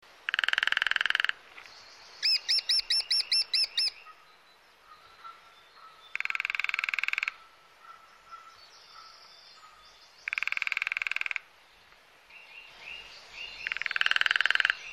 Kleine bonte specht
Kleine-bonte-specht.mp3